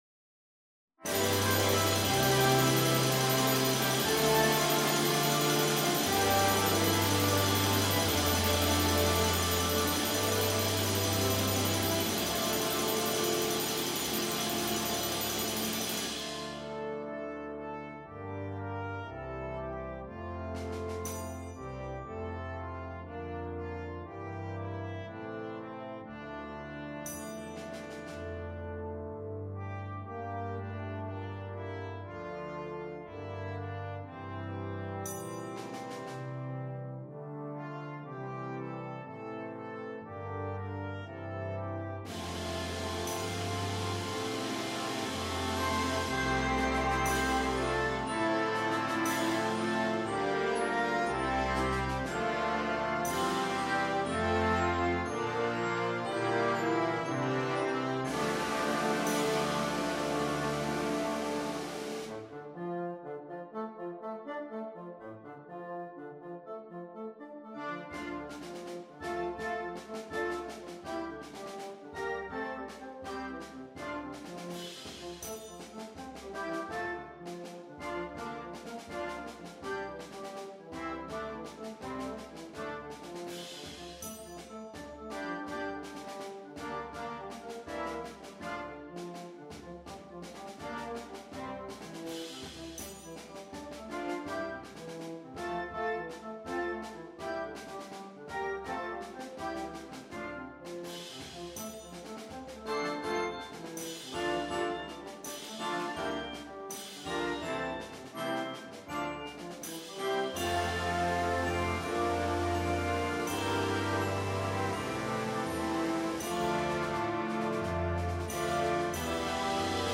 It is arranged for full band and is of medium difficulty.